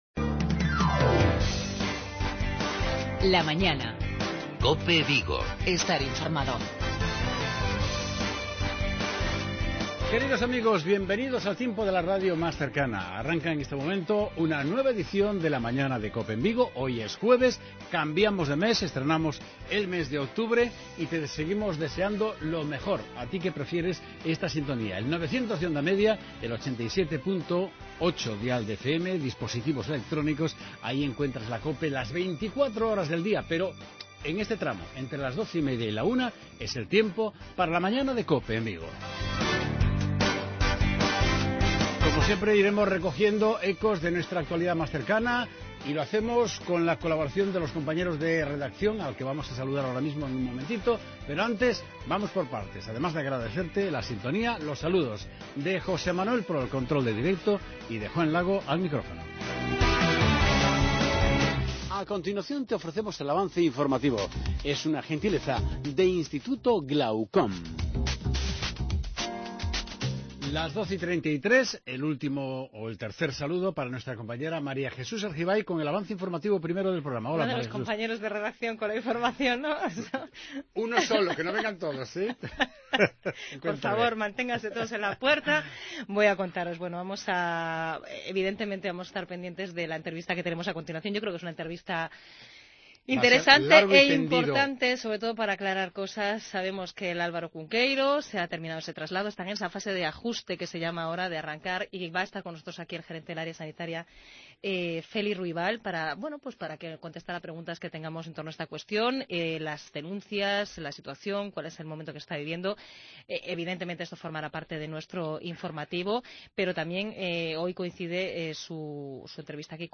Volvemos a tratar la puesta en marcha del nuevo hospital Álvaro Cunqueiro, con una entrevista en profundidad